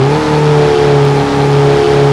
mitsuevox_revdown.wav